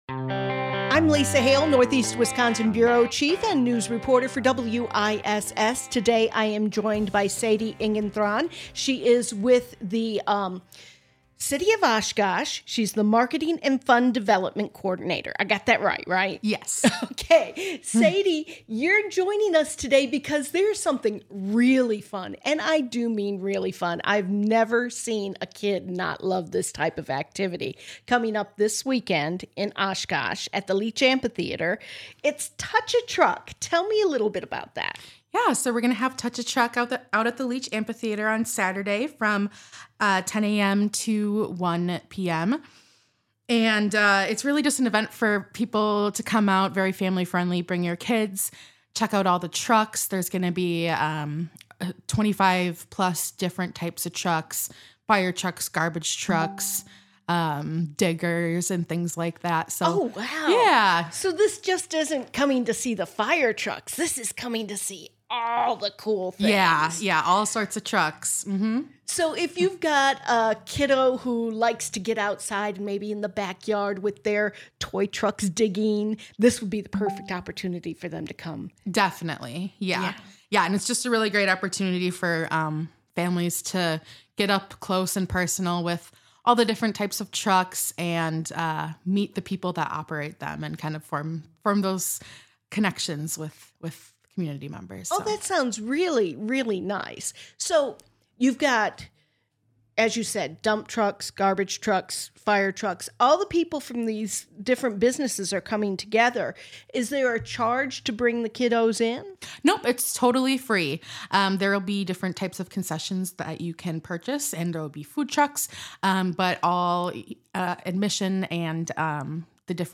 The show will air weekday mornings during local drop ins as part of Mornings with Pat Kreitlow on 96.5 and 98.3 WISS in Appleton and Oshkosh.